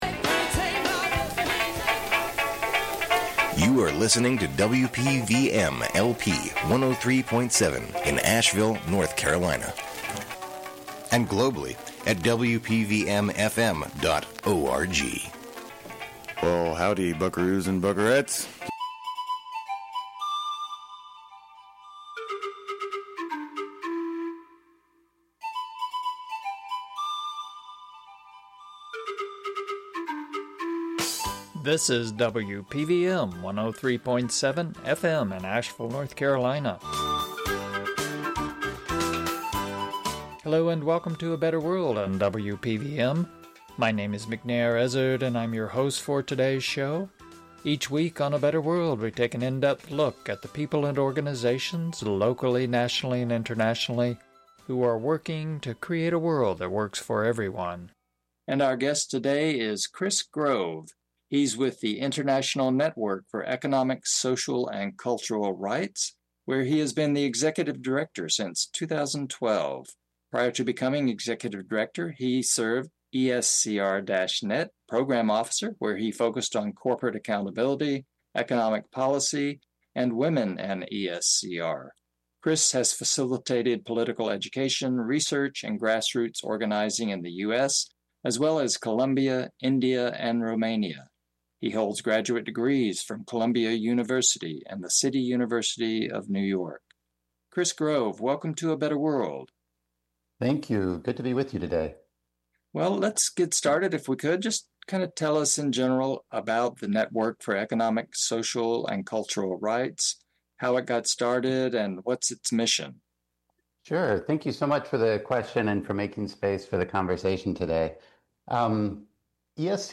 A Better World radio show